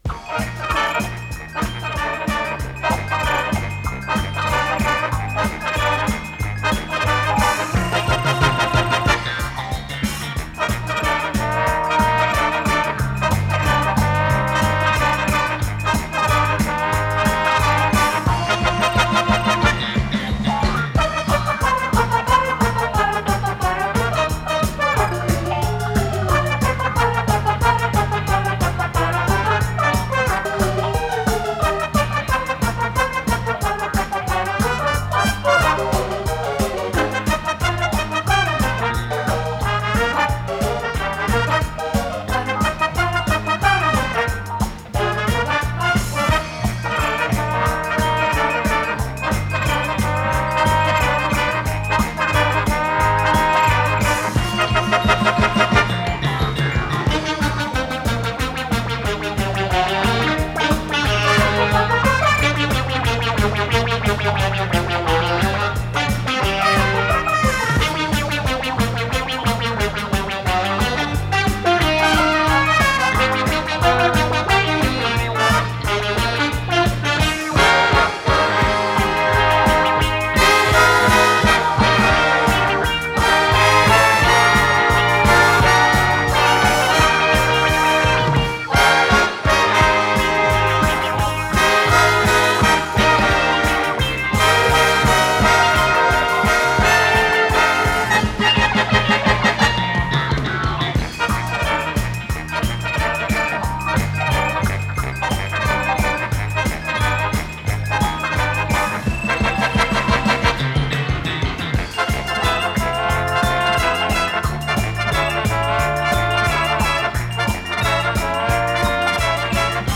вокальная группа (без слов)
Скорость ленты38 см/с
ВариантДубль моно